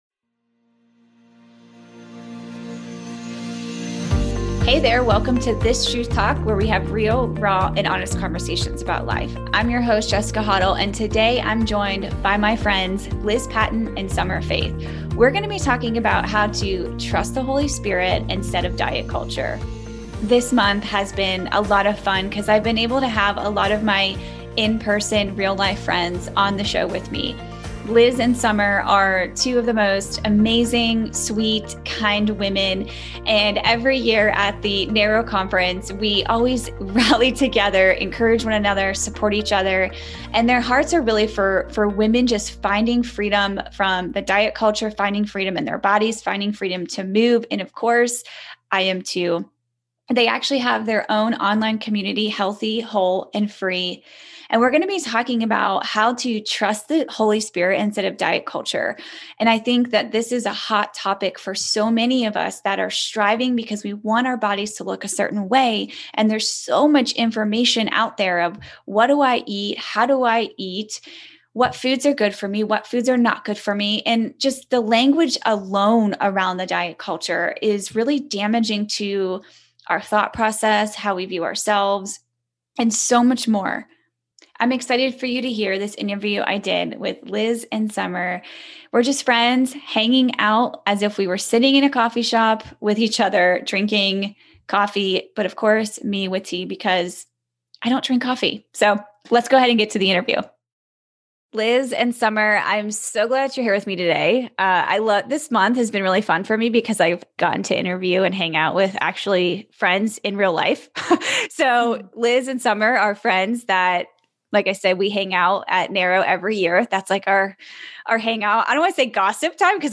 In this truth talk